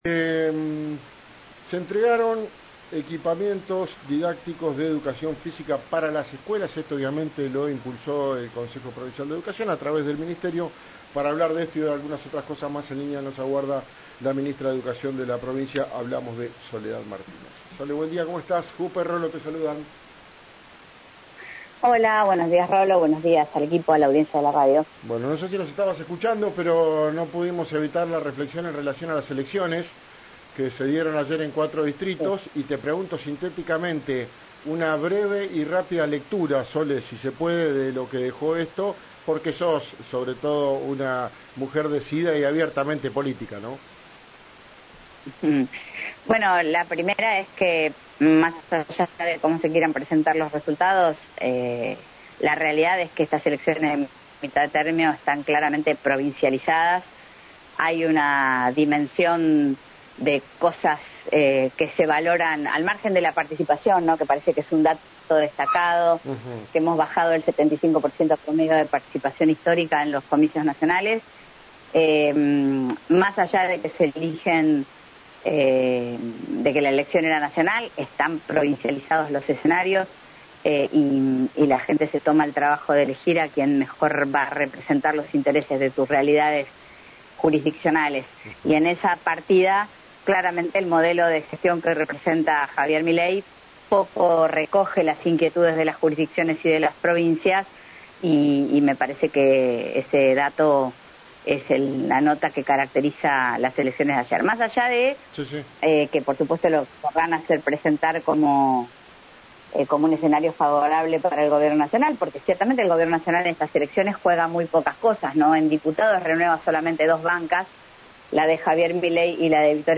La funcionaria dialogó este lunes con RÍO NEGRO RADIO.
Escuchá a la ministra de Educación, Soledad Martínez, en RÍO NEGRO RADIO: